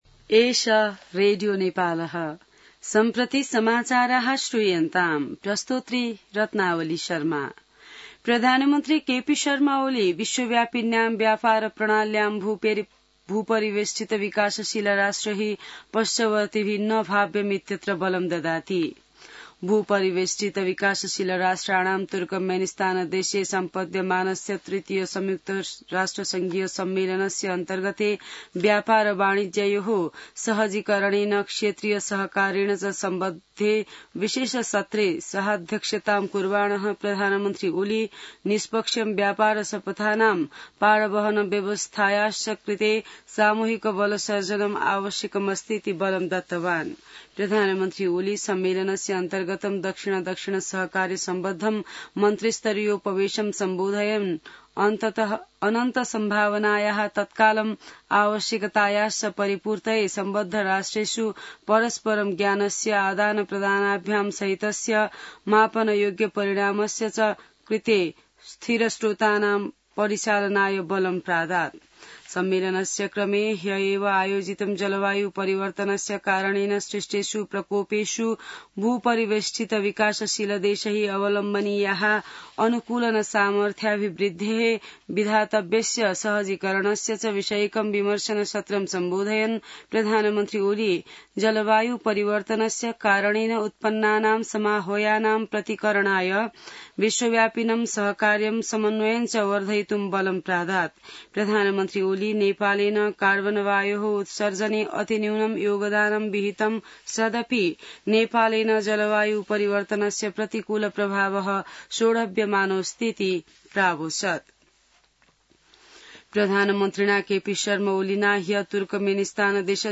संस्कृत समाचार : २२ साउन , २०८२